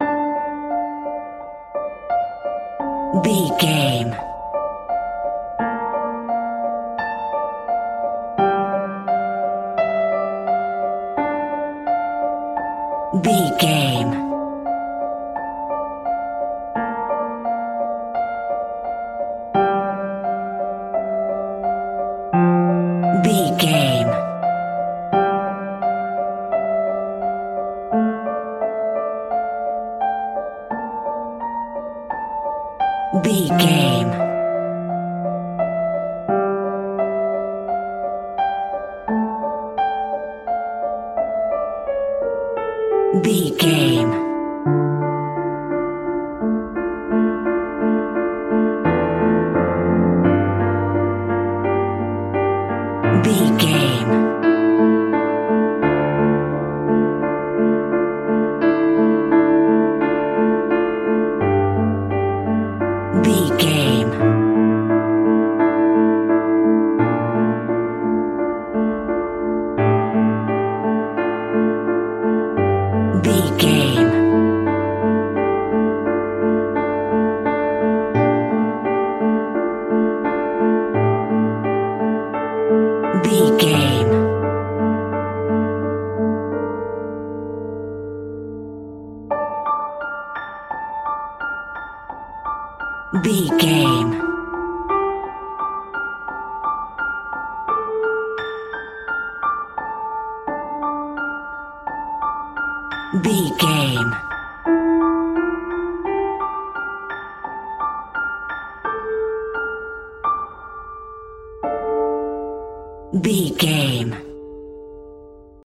Piano Scary Music.
Aeolian/Minor
D
ominous
dark
suspense
eerie
Scary Piano